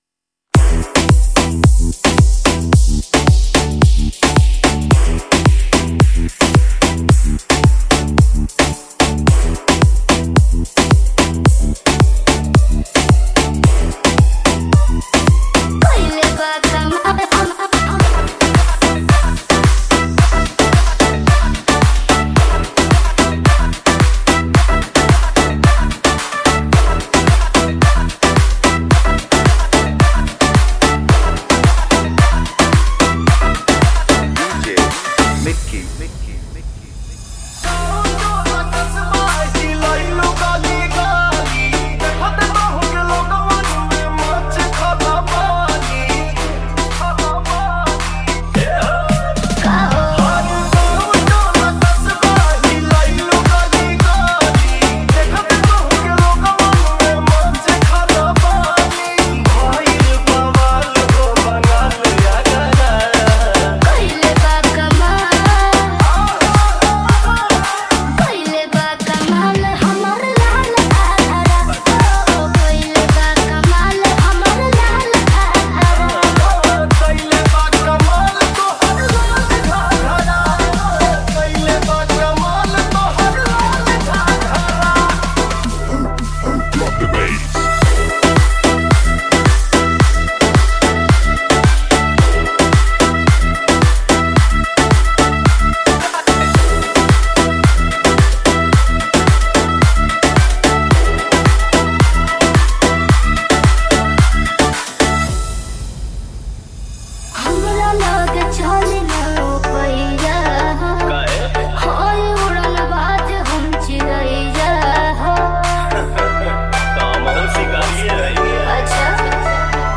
Bhojpuri DJ Remix Songs